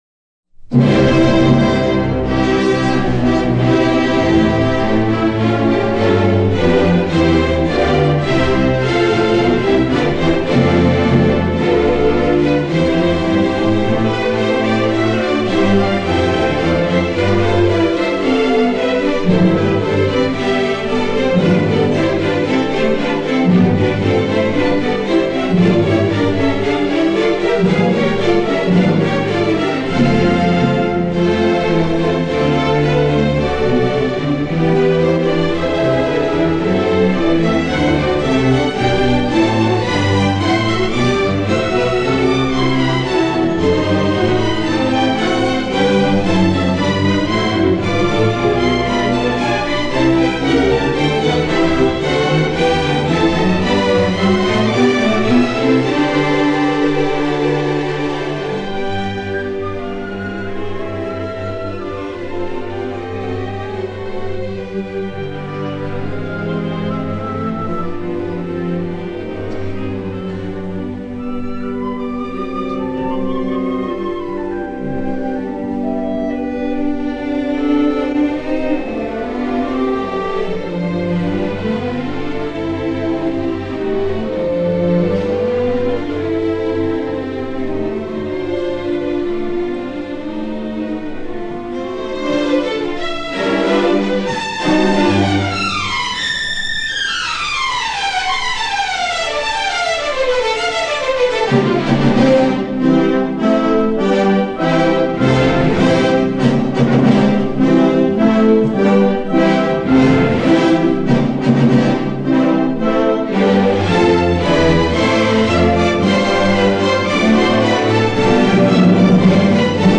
01-Prelude
演出者：Herbert von Karajan　卡拉揚（指揮）
Chor und Orchester der Bayreuther Festspiele　拜魯特音樂節管弦樂團與合唱團
音樂類型：古典音樂
1951年 拜魯特現場錄音